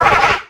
Cri de Chuchmur dans Pokémon X et Y.